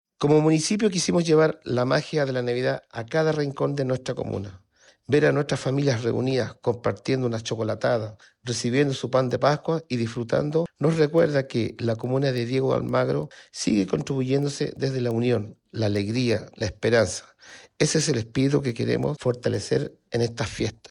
El alcalde Mario Araya Rojas destacó la relevancia de mantener vivas estas instancias
CunZa-1-Alcalde-Mario-Araya-Rojas-DDA.mp3